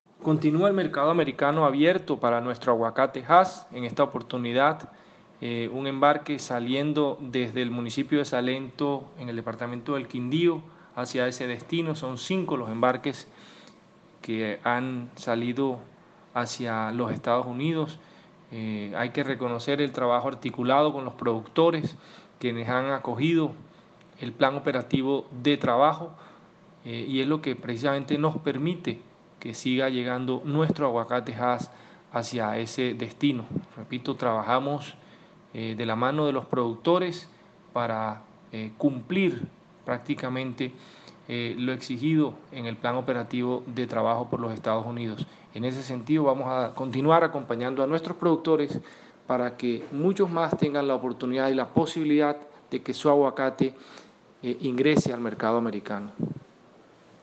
Declaraciones-gerente-general-del-ICA_1.mp3